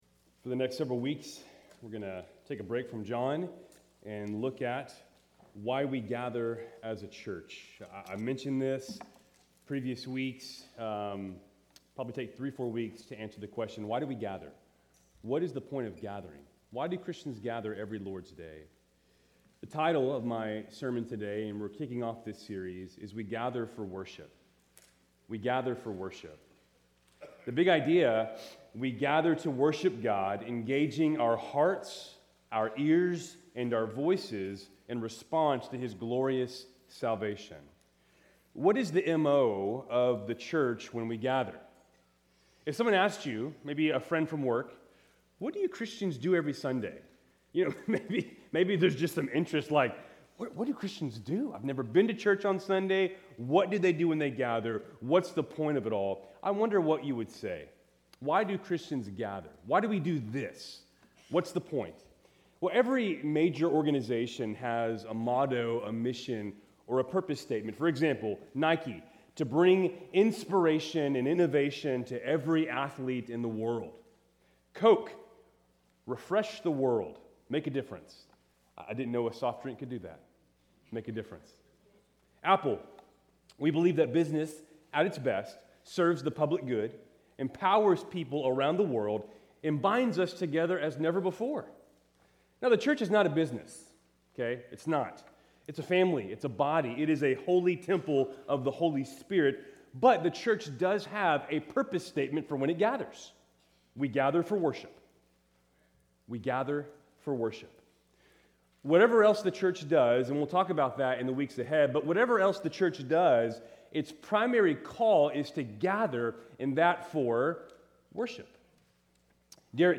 Keltys Worship Service, September 7, 2025